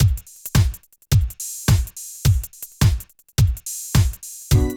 47 DRUM LP-L.wav